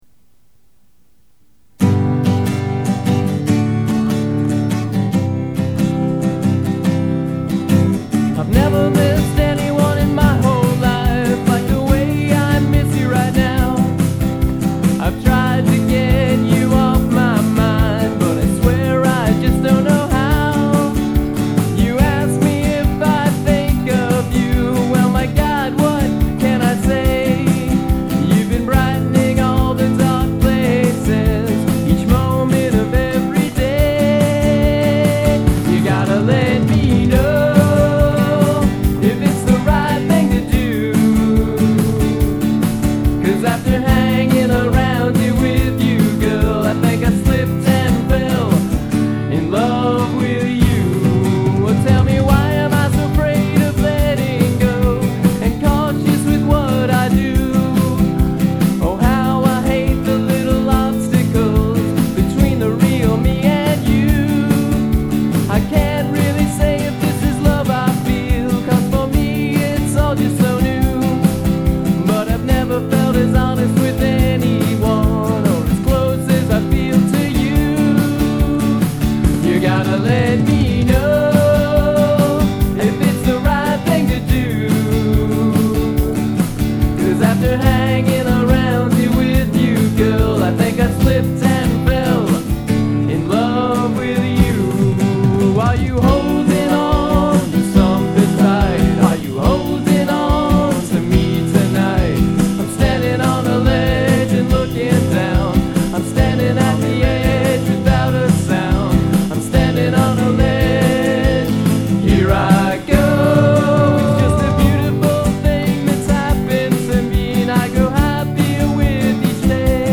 This is the only song I've ever written. Recorded at Acme Studios in Chicago